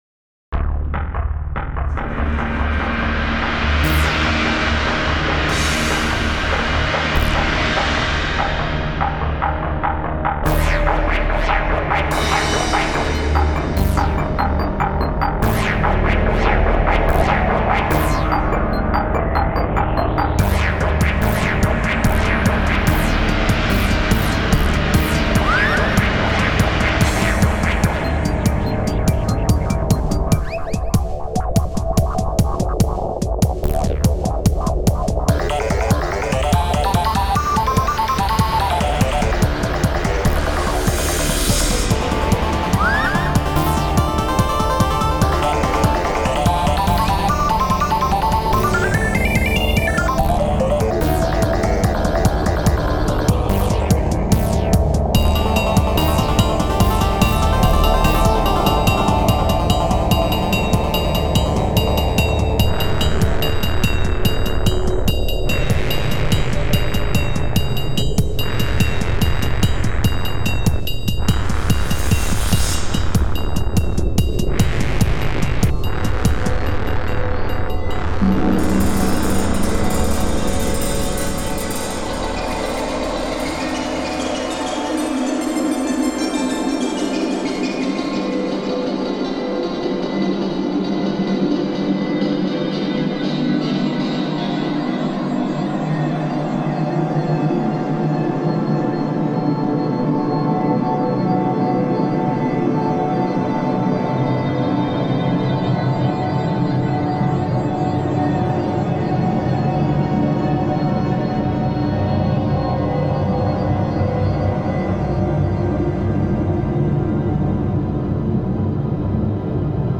Home > Music > Ambient > Medium > Chasing > Restless